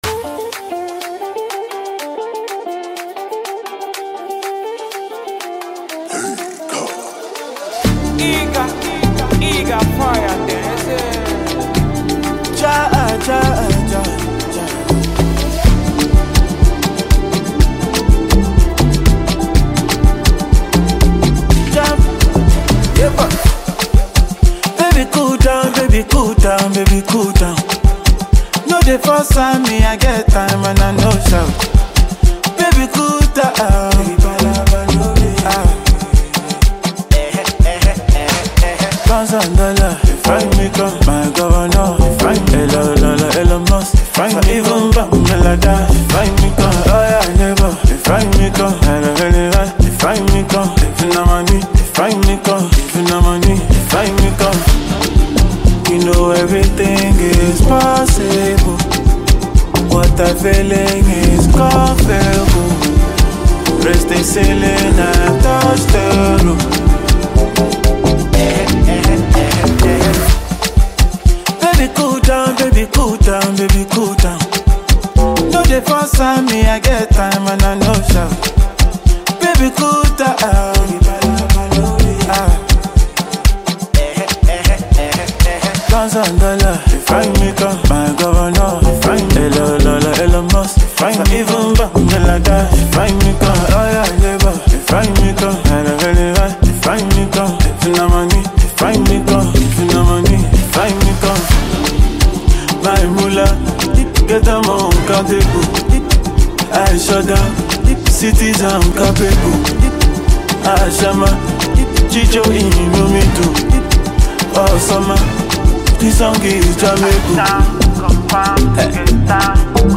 catchy jam
It is a banger.